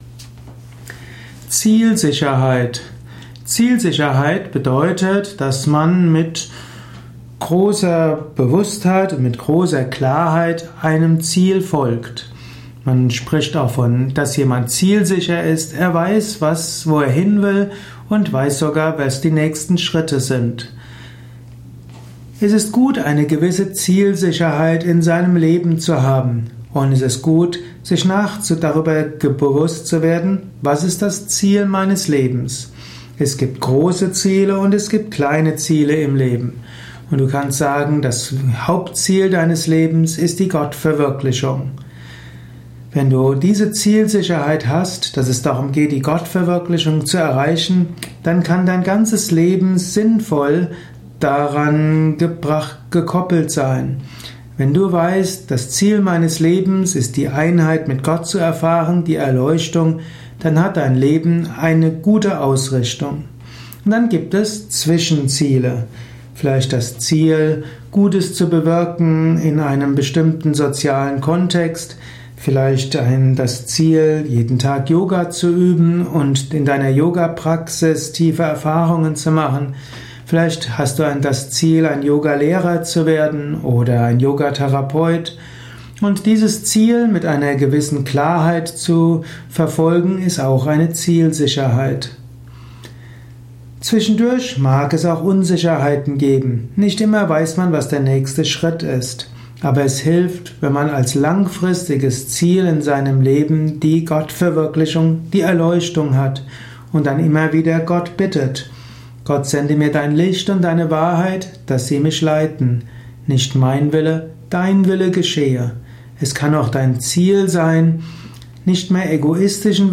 Verstehe etwas mehr über das Thema Zielsicherheit in einem kurzen Spontan-Audiovortrag.
Dieser Audio Podcast über \" Zielsicherheit \" ist die Tonspur eines Videos, zu finden im You